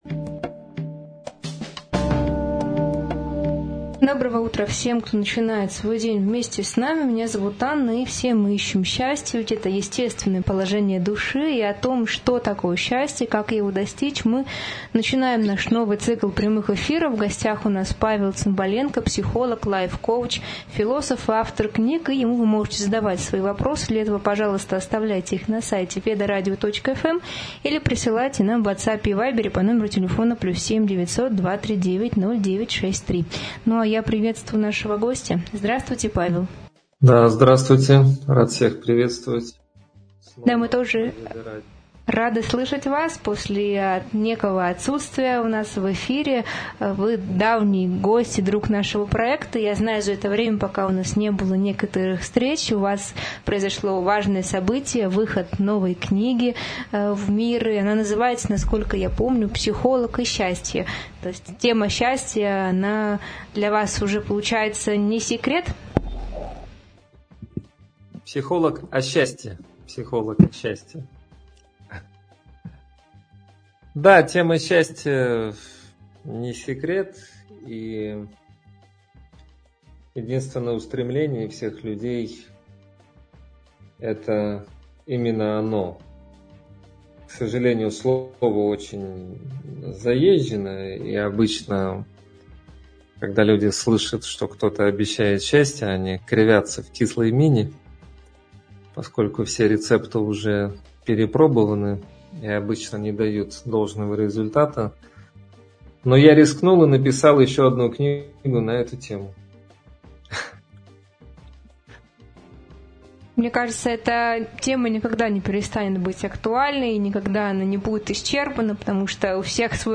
Сообщается, что зрители могут задать свои вопросы через сайт или по телефону, что делает беседу интерактивной.